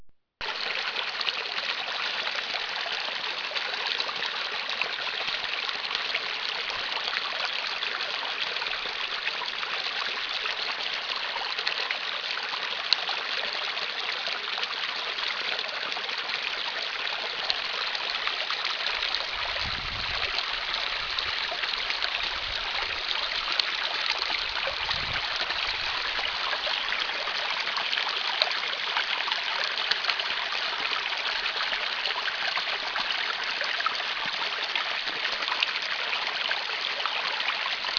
lähteen lähellä purojen risteyksessä tipauttaa rinkan ja pystyttää leirin. Siellä kuulosti
Tästä lähtee lähde